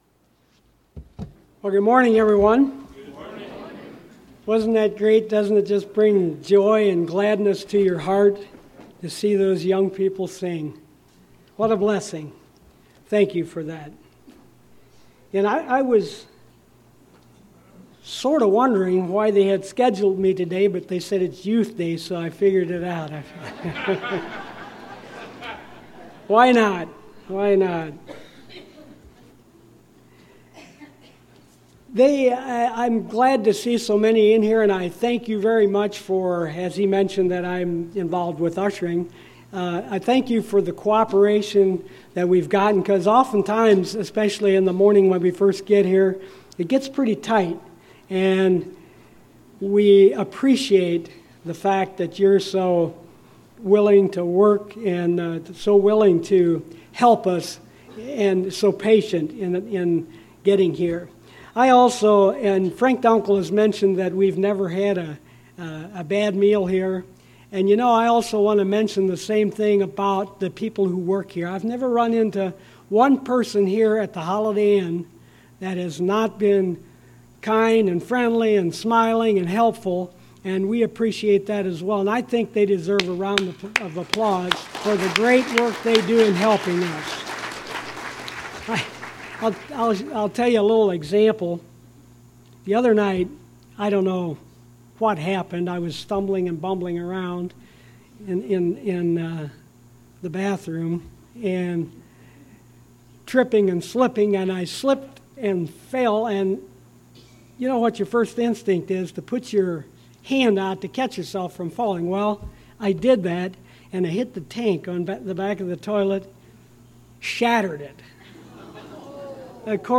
This sermon was given at the Cincinnati, Ohio 2016 Feast site.